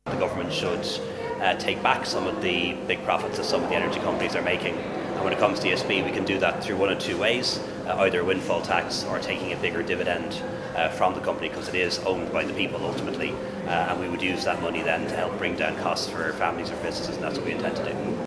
But Tánaiste Leo Varadkar says the government will be clawing back some of the large profits from the company: